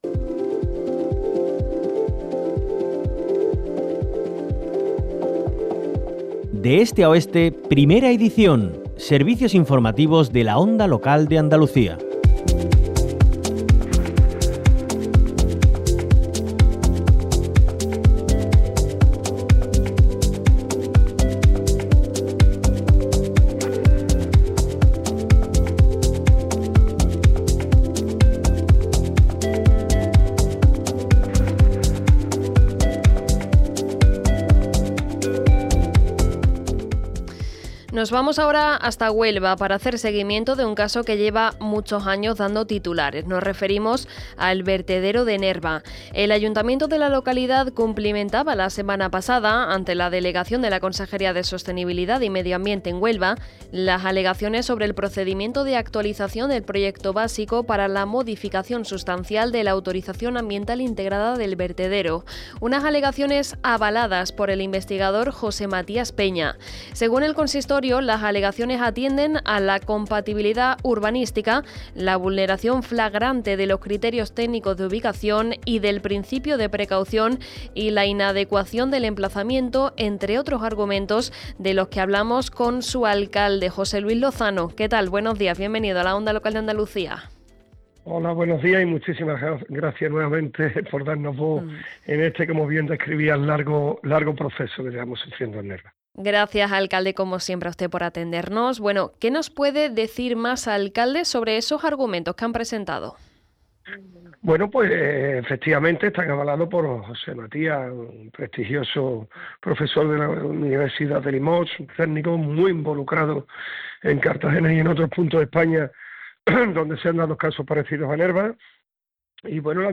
Así lo ha dicho José Luis Lozano en una entrevista en la Onda Local de Andalucía, donde ha recordado que el pasado viernes el consistorio presentó alegaciones antes la Delegación de la Consejería de Sostenibilidad y Medio Ambiente en Huelva.
JOSÉ LUIS LOZANO – ALCALDE DE NERVA